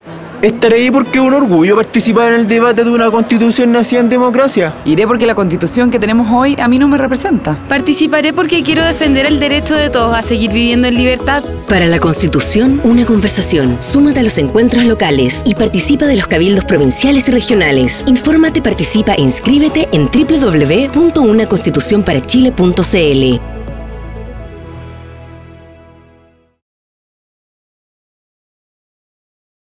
Audio con distintas voces testimoniales que llaman a la participación en el proceso constituyente, especialmente en los encuentros locales, cabildos provinciales y regionales 9.